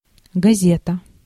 Ääntäminen
GenAm: IPA : /ˈnuːzˌpeɪpɚ/